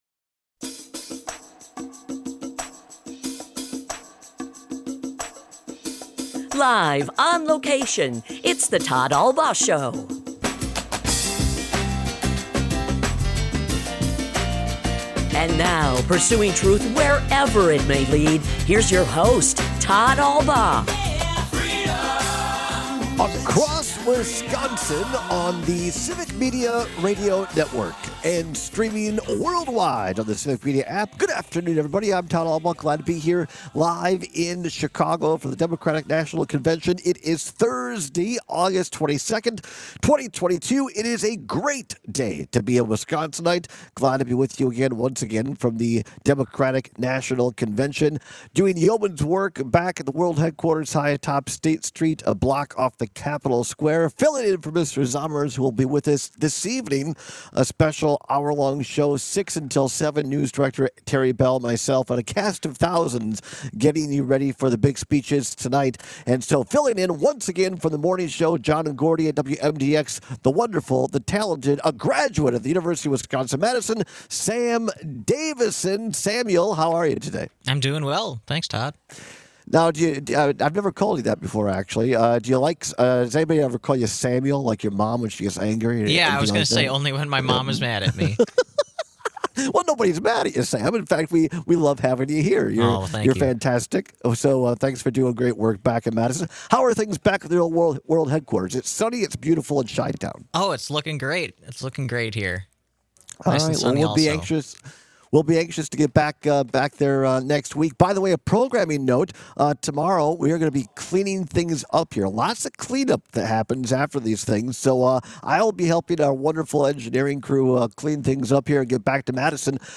live at the DNC for the fourth and final day